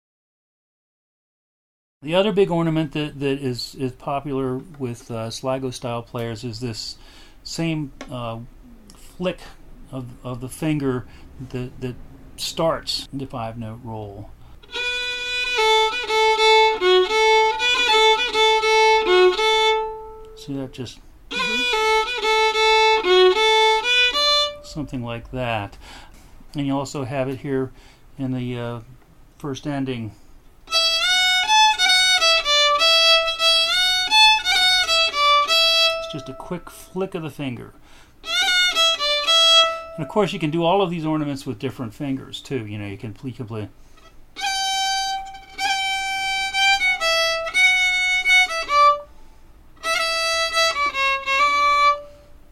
Description: Another very common Sligo ornament.
Usually they are performed with the next higher (in pitch) finger flicking the string very lightly to create more of an interuption of the fundamental note.